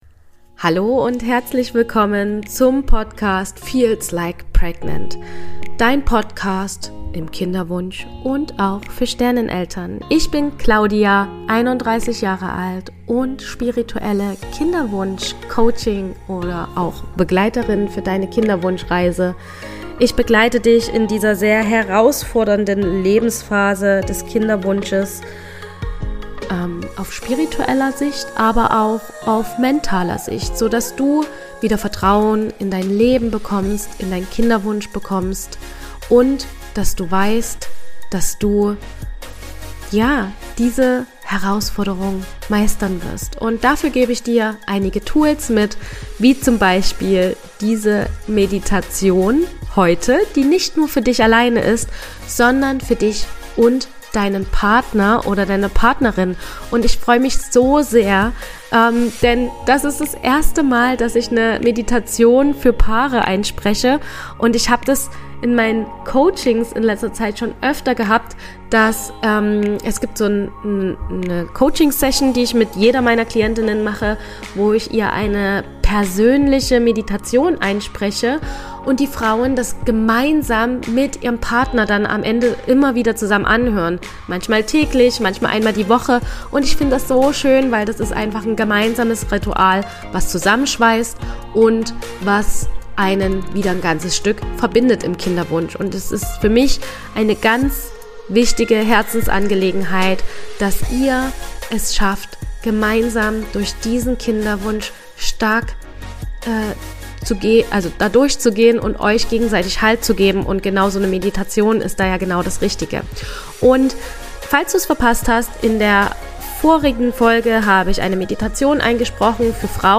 Diese Meditation ist speziell für Paare konzipiert, die sich ein Kind wünschen und auf körperlicher sowie energetischer Ebene in Verbindung treten möchten. Die Meditation beginnt bei Minute 3.